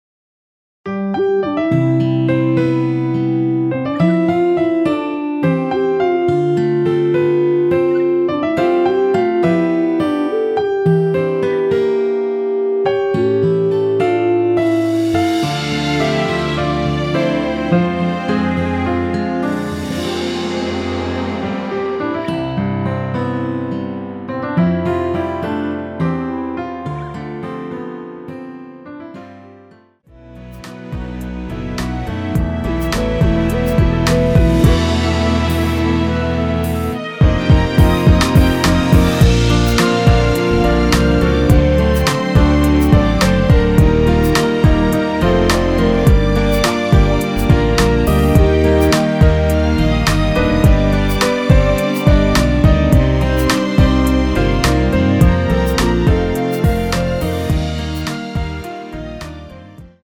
◈ 곡명 옆 (-1)은 반음 내림, (+1)은 반음 올림 입니다.
노래방에서 노래를 부르실때 노래 부분에 가이드 멜로디가 따라 나와서
앞부분30초, 뒷부분30초씩 편집해서 올려 드리고 있습니다.
중간에 음이 끈어지고 다시 나오는 이유는